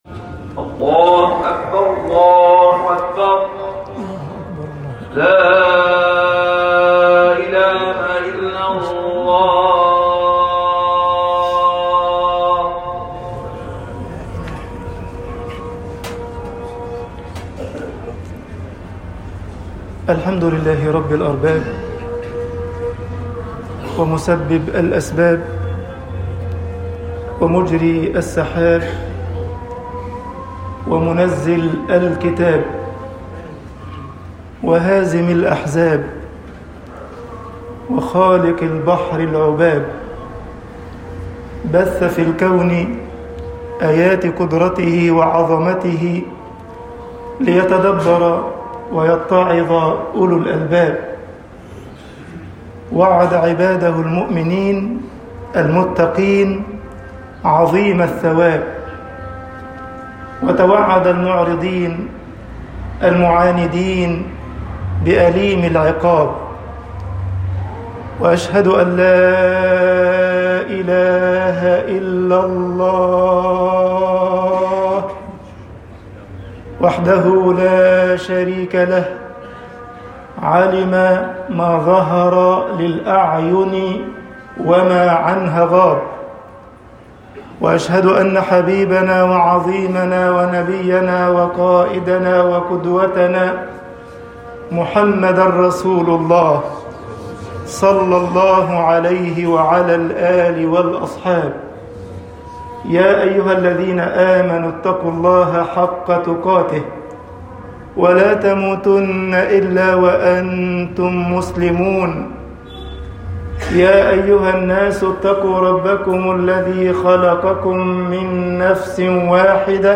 خطب الجمعة - مصر أمَّةُ الْإسْلَامِ خَيْرُ الْأمَمِ طباعة البريد الإلكتروني التفاصيل كتب بواسطة